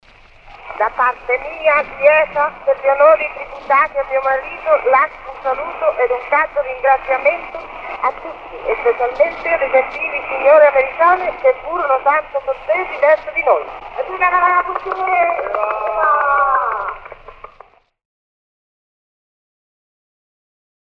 Auch Elvira bedankt sich (Columbia Tonstudios 1907).
Also Elvira expresses her thanks (Columbia Records 1907). It remains unclear why she thanks explicitly the American women.